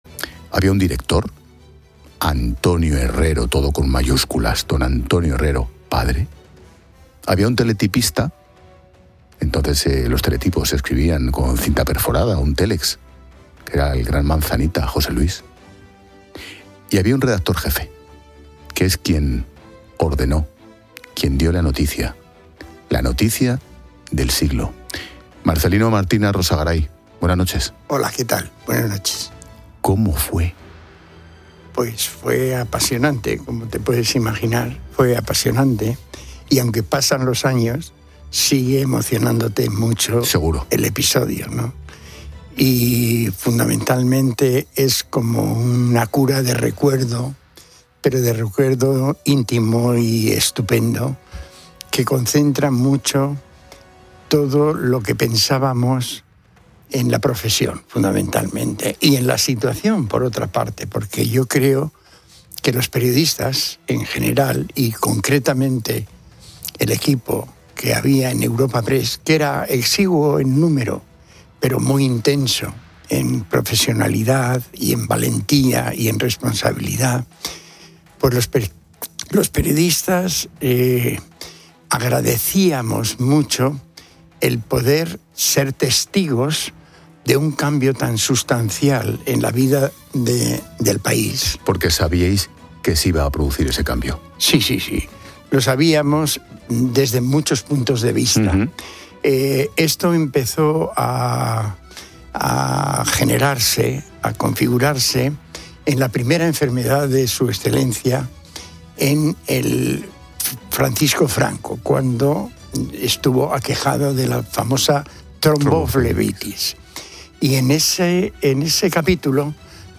Ángel Expósito habla con